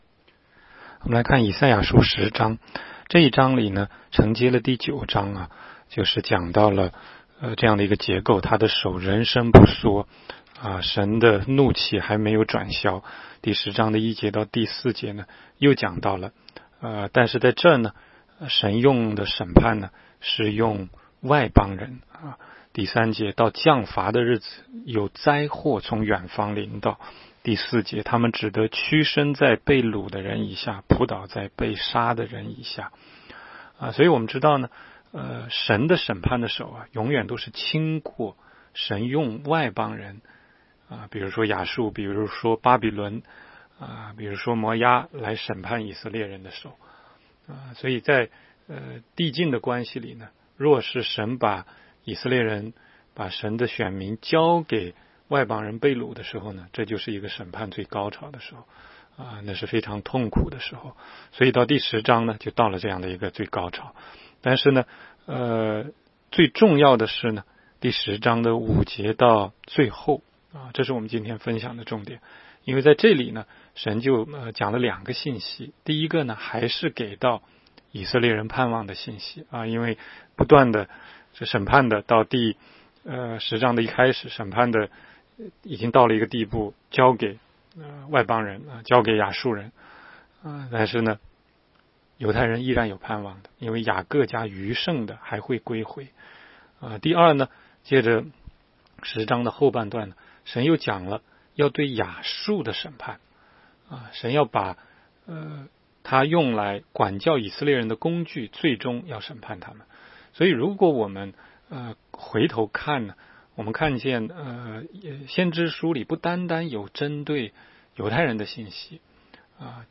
16街讲道录音 - 每日读经 -《 以赛亚书》10章
每日读经-赛10章.mp3